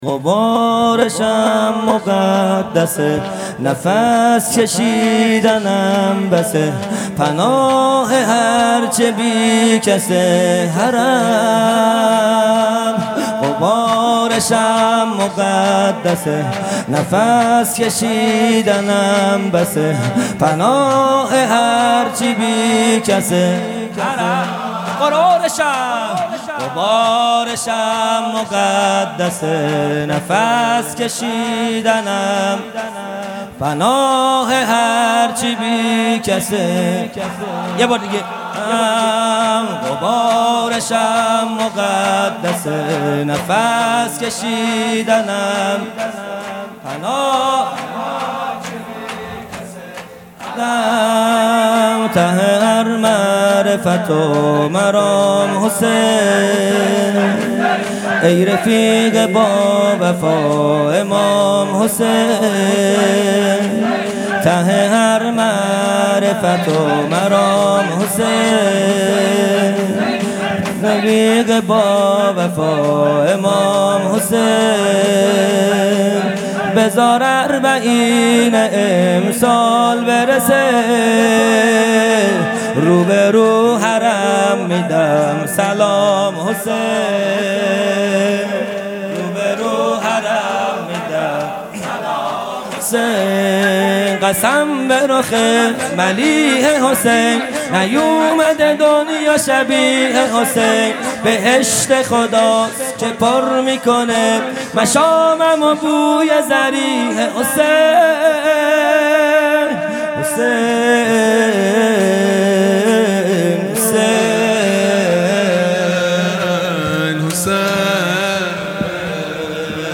خیمه گاه - هیئت بچه های فاطمه (س) - شور | غبارشم مقدسه نفس کشیدنم بسه | دوشنبه ۱۷ مرداد ماه ۱۴۰۱